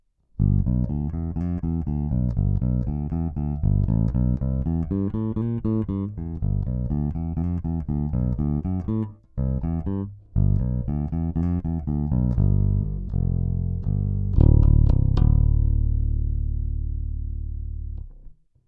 Snímač krk, dvoucívka, paralelně (prsty)